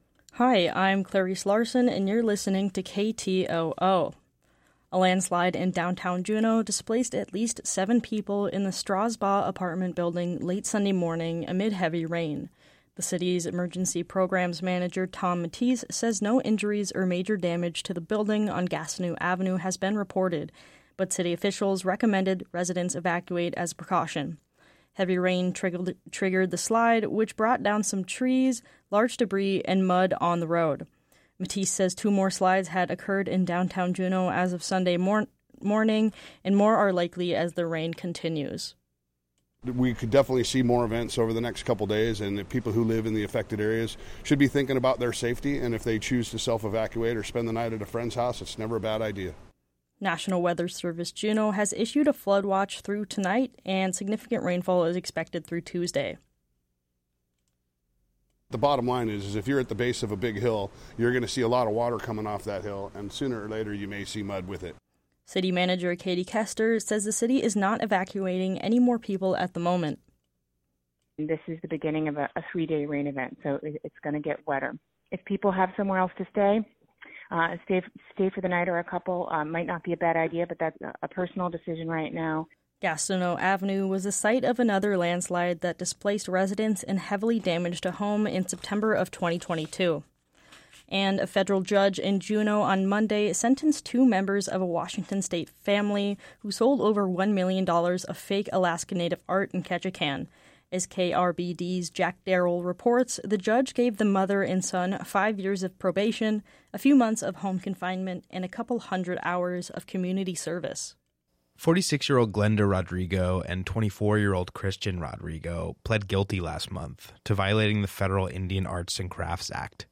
Newscast - Monday, July 15, 2024